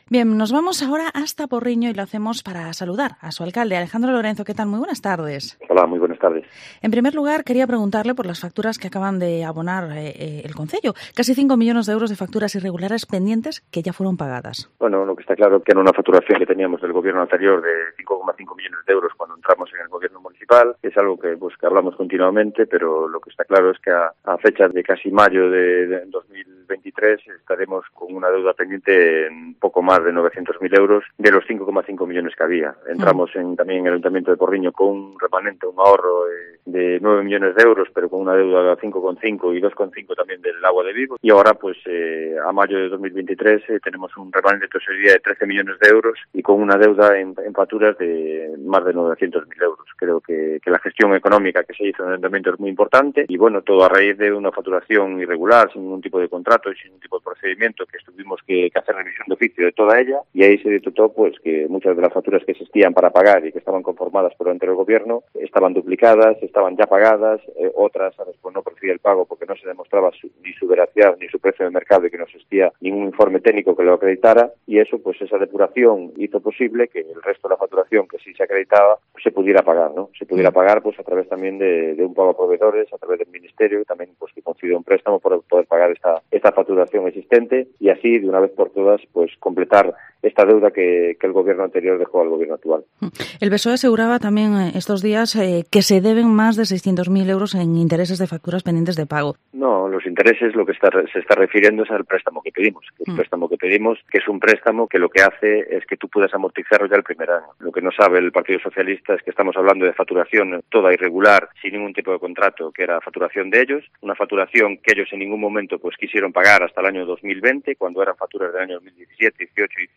Entrevista Alcalde de Porriño, Alejandro Lorenzo